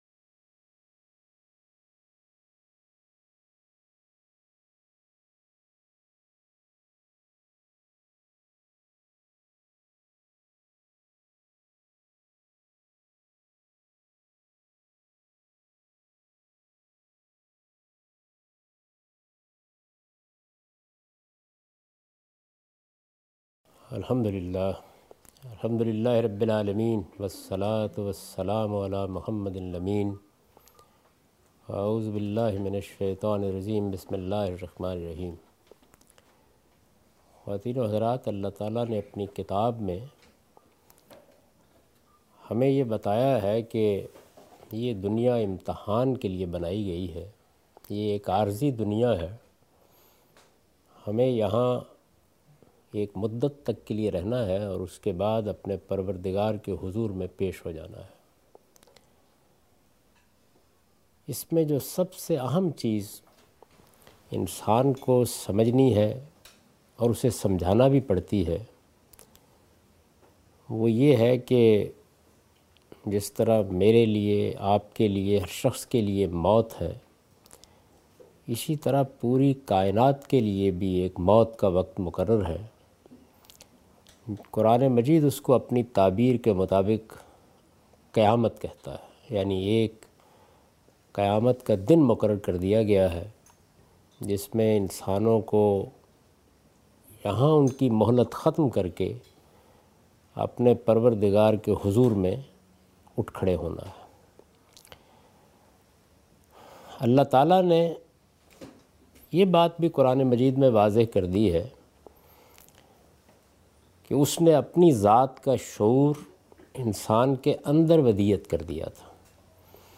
Javed Ahmad Ghamid speaks about "Law of Itmam al-Hujjah" during his Australia visit on 08th October 2015.